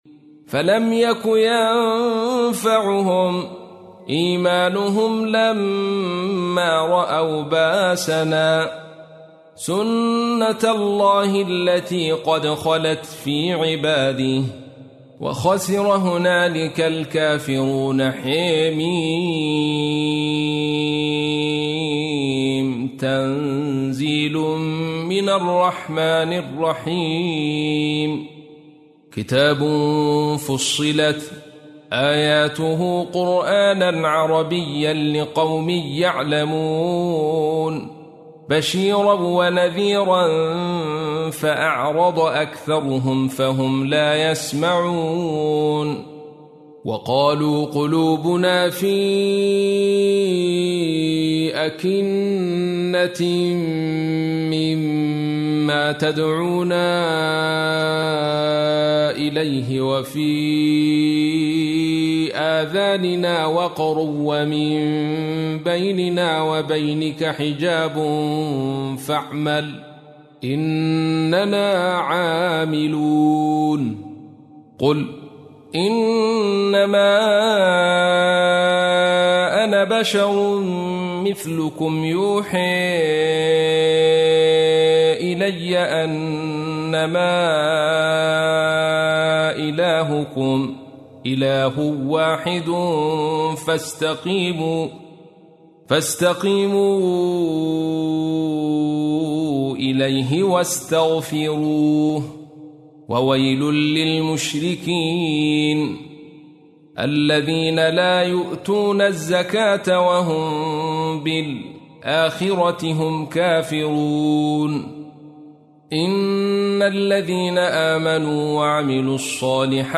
تحميل : 41. سورة فصلت / القارئ عبد الرشيد صوفي / القرآن الكريم / موقع يا حسين